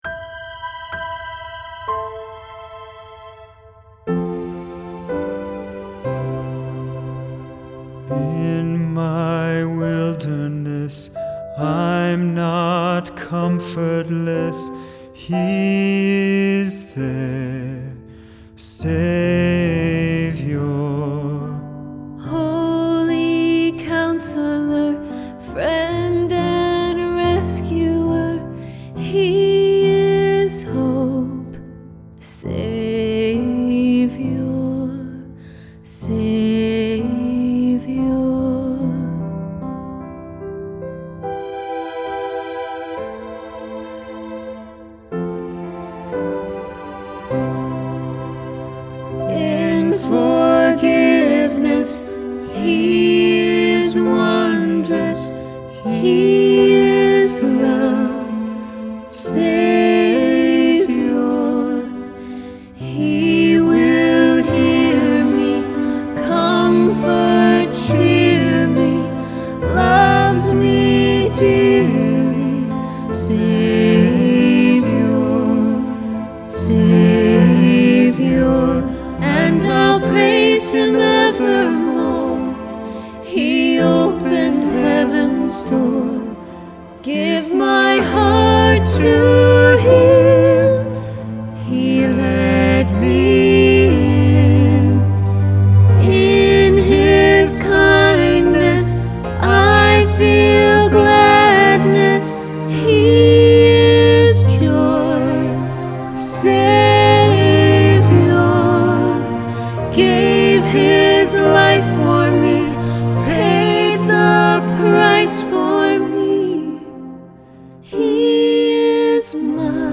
Song Samples in mp3 format